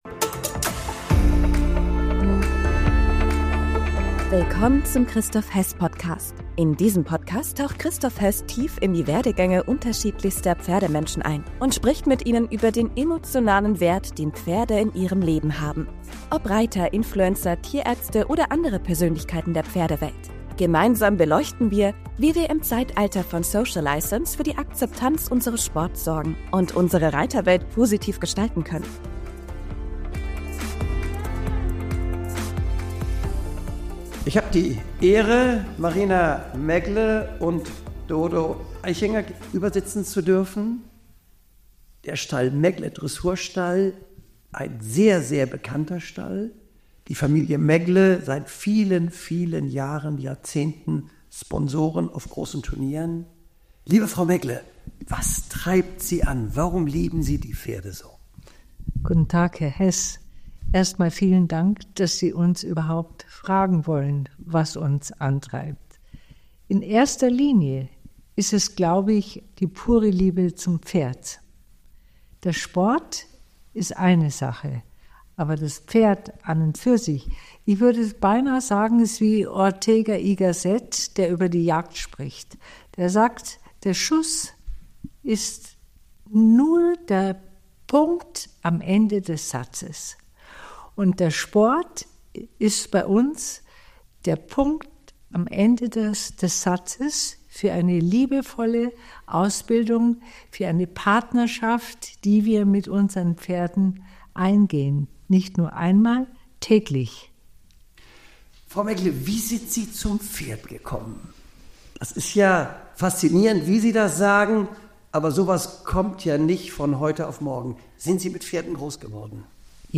Ein Gespräch voller Herz, Weisheit und echter Pferdepassion.